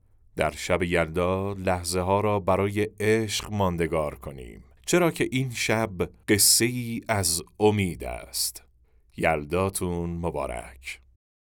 نریشن شب یلدا